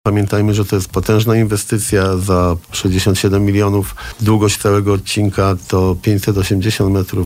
A reszta do czerwca przyszłego roku – mówi Jarosław Klimaszewski, prezydent Bielska-Białej.